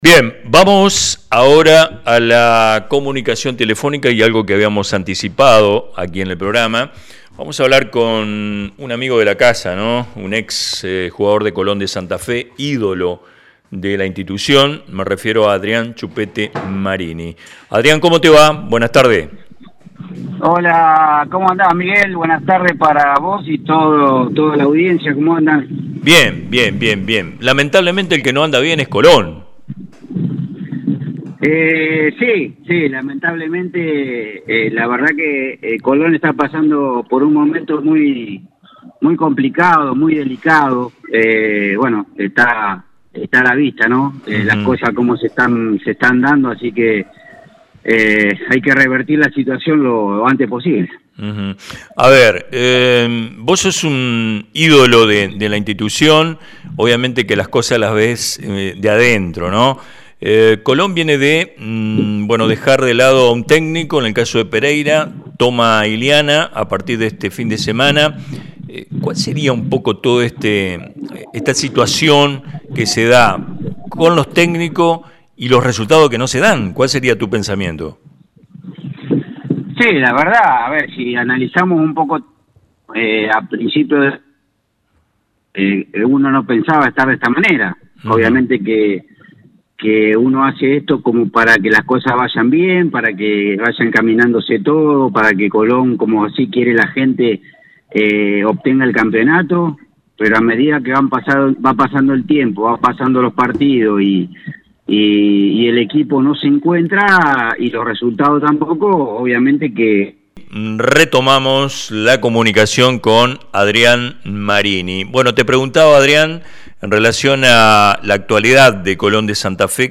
El exjugador e ídolo de Colón de Santa Fe, Adrián “Chupete” Marini, habló con Radio Ideal sobre el difícil presente futbolístico que atraviesa el club en el torneo de la Primera Nacional.